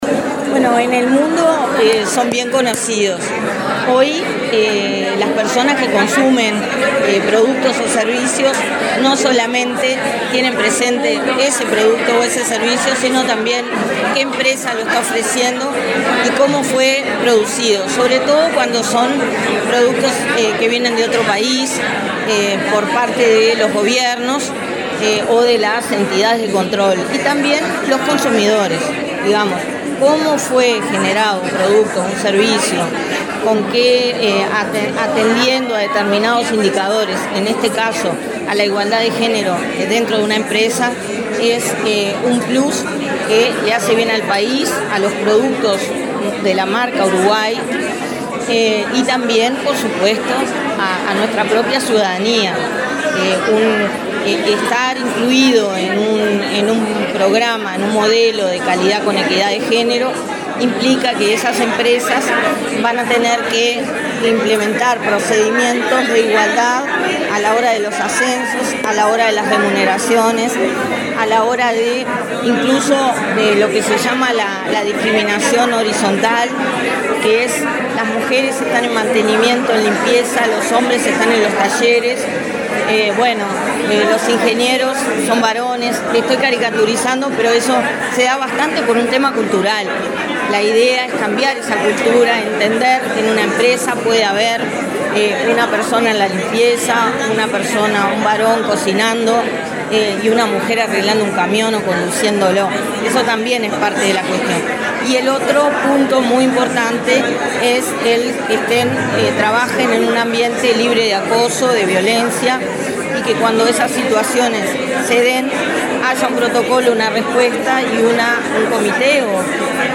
Declaraciones de la directora de Inmujeres, Mónica Bottero
Declaraciones de la directora de Inmujeres, Mónica Bottero 22/03/2023 Compartir Facebook Twitter Copiar enlace WhatsApp LinkedIn El Instituto Nacional de las Mujeres (Inmujeres), del Ministerio de Desarrollo Social, presentó, este miércoles 22 en Montevideo, la cuarta versión del Modelo de Calidad con Equidad. La directora de la entidad, Mónica Bottero, explicó a la prensa la importancia de esta herramienta.